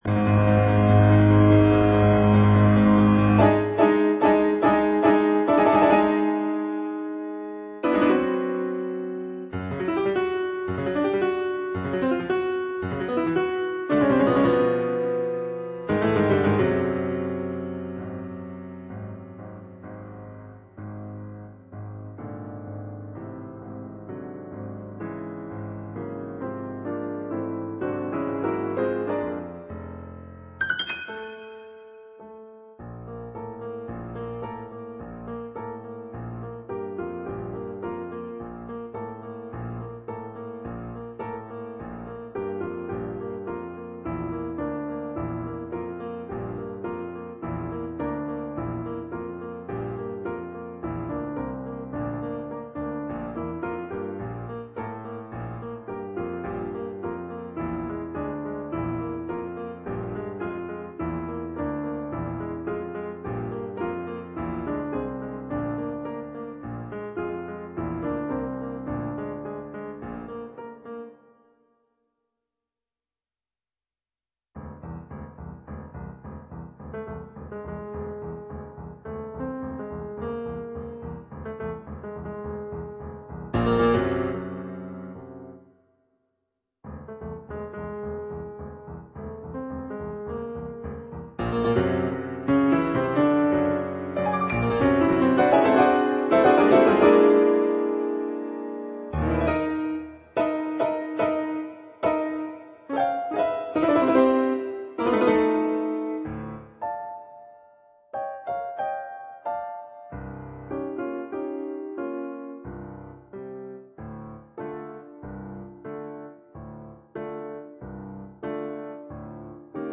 on Yamaha digital pianos.